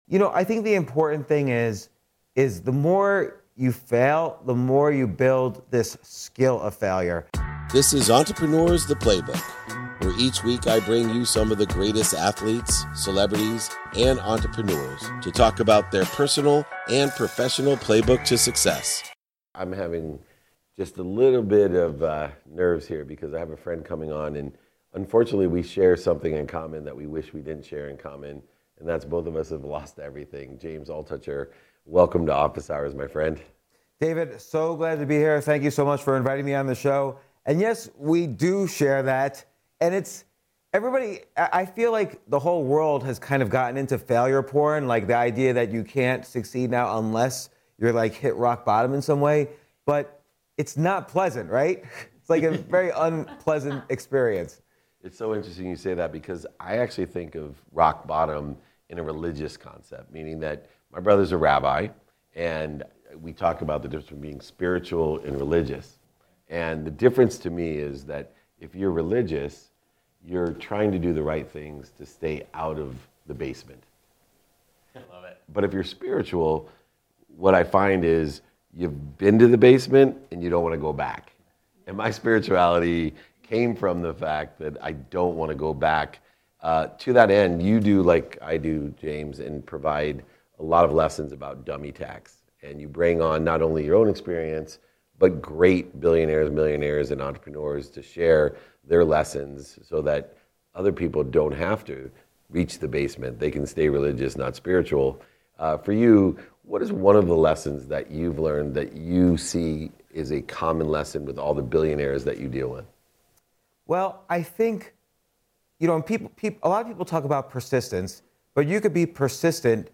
Today's episode is from a conversation with James Altucher, a true renaissance man with expertise in finance, podcasting, comedy, and entrepreneurship.